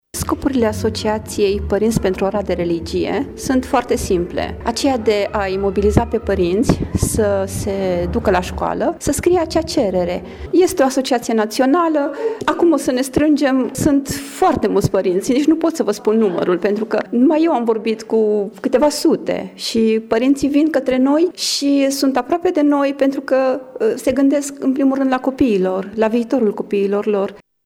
Şedinţa oficială de constituire a filialei locale a avut loc în această după-masă la Sala Ansamblului „Mureşul” din cartierul Dâmbul Pietros.